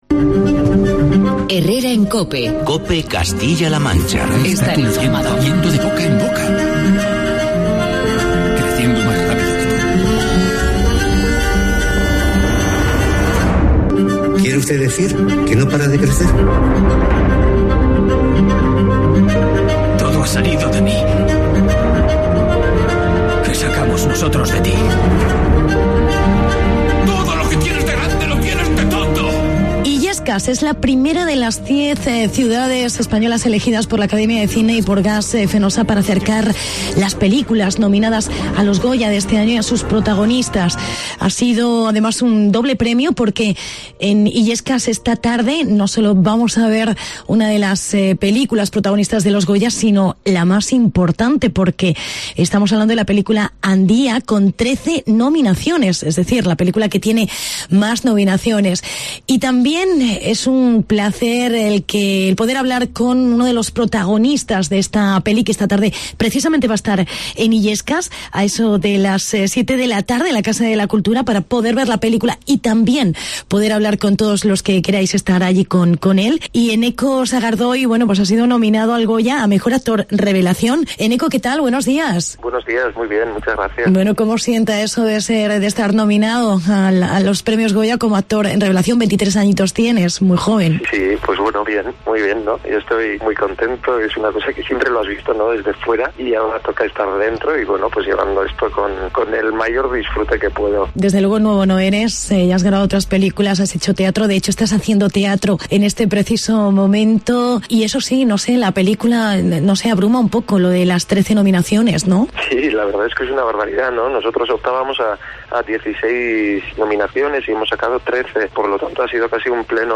Entrevsita con Eneko Sagardoy